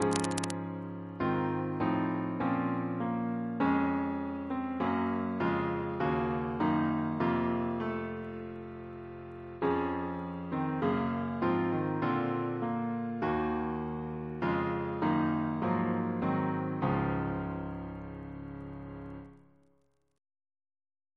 Double chant in D♭ Composer